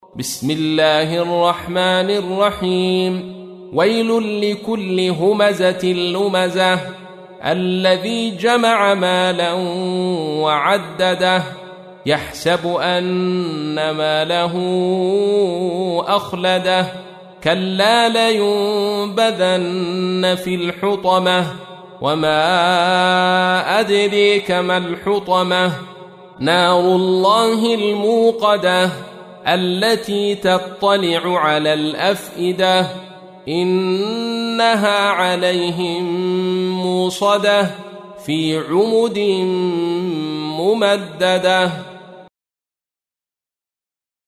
تحميل : 104. سورة الهمزة / القارئ عبد الرشيد صوفي / القرآن الكريم / موقع يا حسين